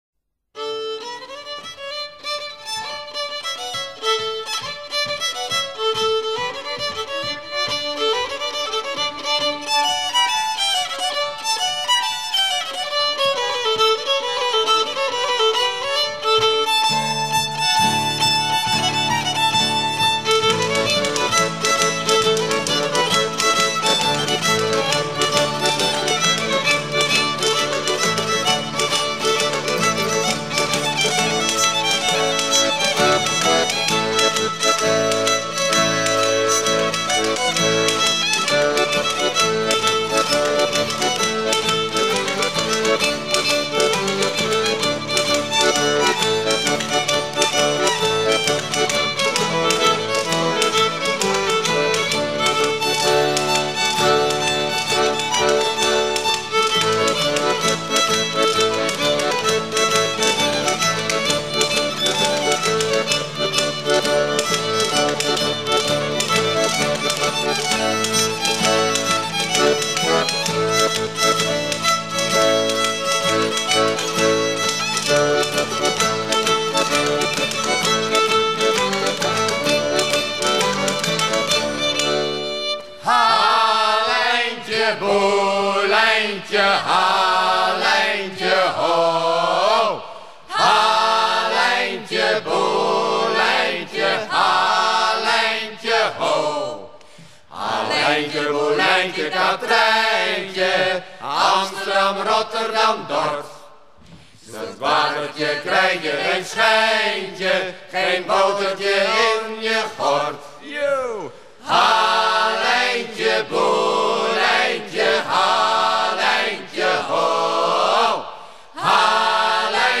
air de danse suivi d'un chant de halage
Pièce musicale éditée